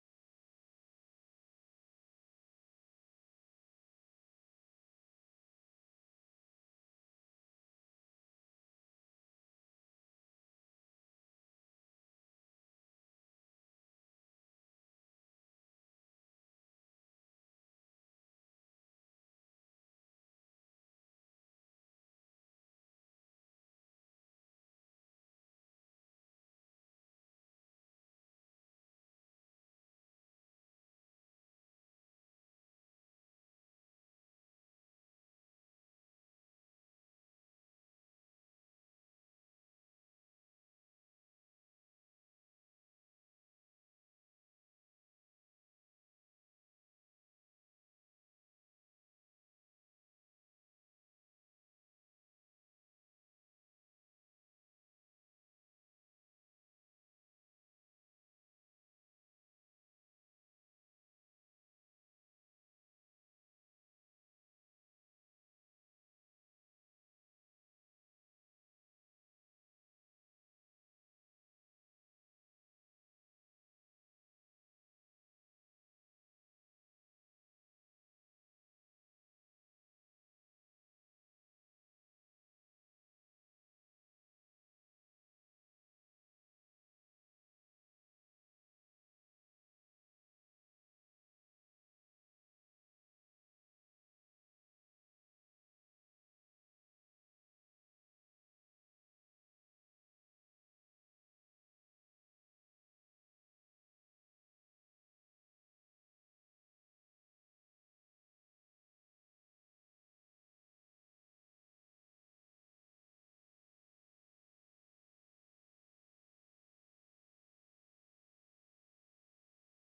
ارسل فتوى عبر "الواتساب" ينبوع الصوتيات الشيخ محمد بن صالح العثيمين فوائد من التعليق على القواعد والأصول الجامعة - شرح الشيخ محمد بن صالح العثيمين المادة 55 - 148 تابع القاعدة 33 إذا تزاحمت المصالح قدم الأعلى...